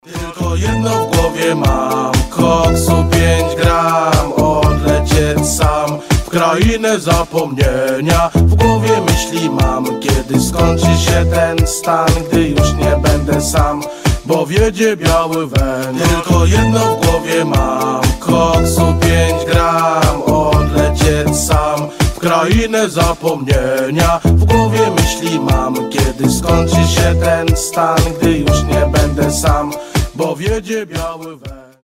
• Качество: 320, Stereo
Хип-хоп
веселые